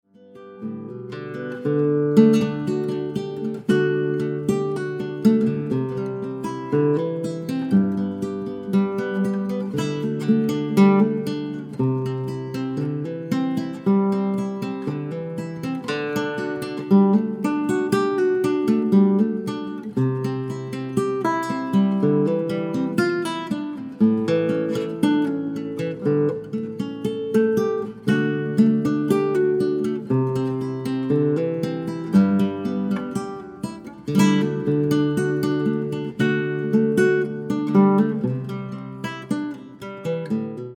solo nylon string guitar